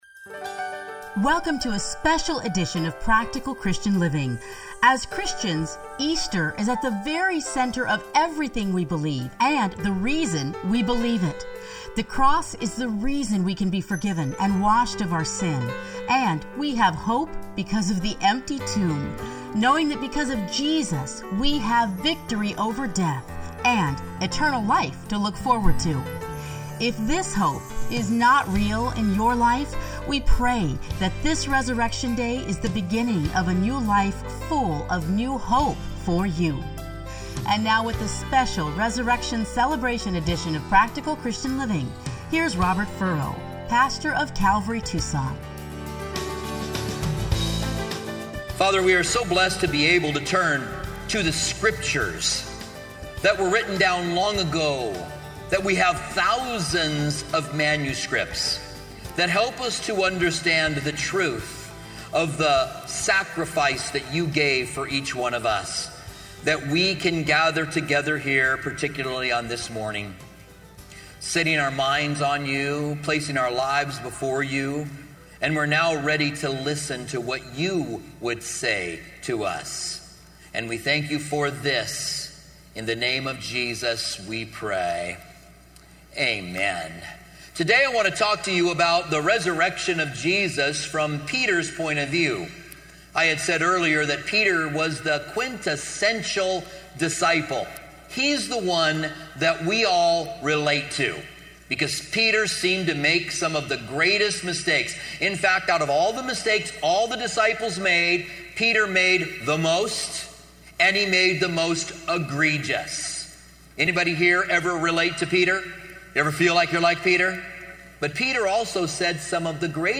Listen here to the 2017 Easter message.